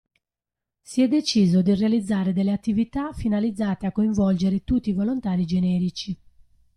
Hyphenated as de‧cì‧so Pronounced as (IPA) /deˈt͡ʃi.zo/